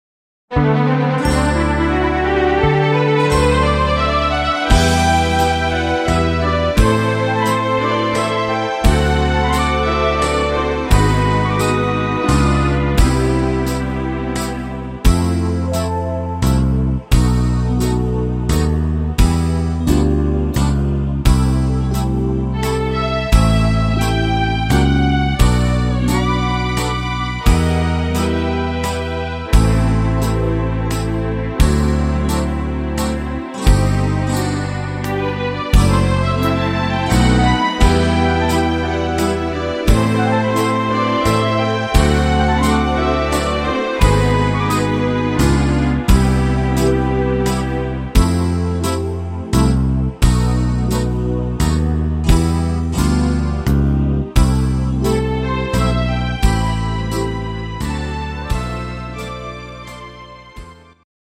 Slow Waltz Version